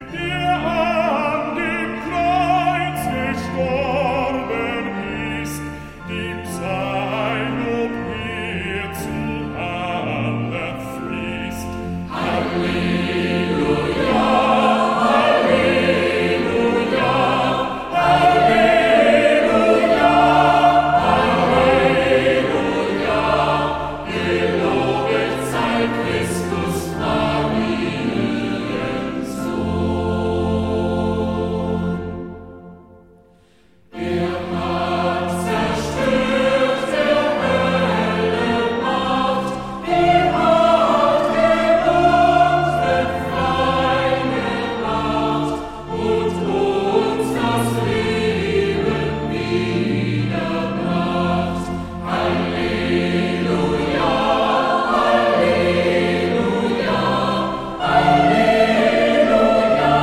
Chormusik/Evangeliumslieder